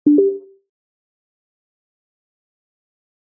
notification-sound-2-25ffae28.mp3